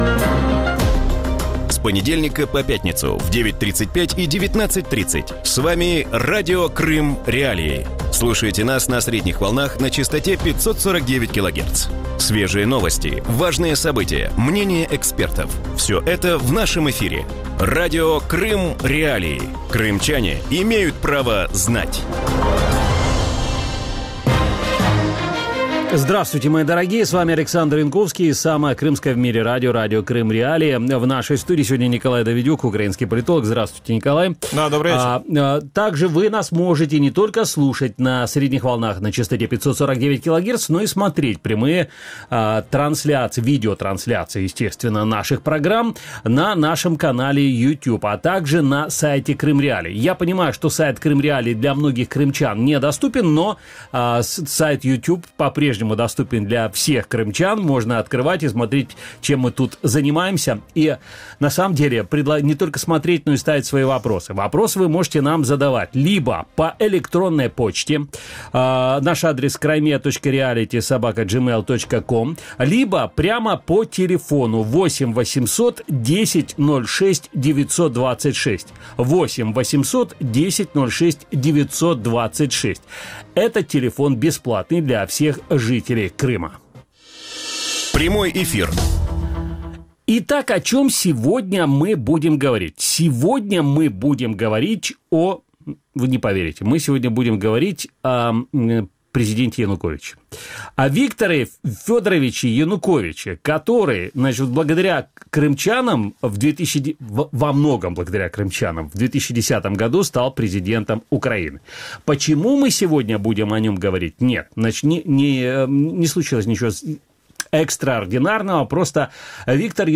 В вечернем эфире Радио Крым.Реалии говорят о допросе бывшего президента Украины Виктора Януковича. Какие подробности сообщил Виктор Янукович об аннексии Крыма и почему Кремль дал экс-президенту слово?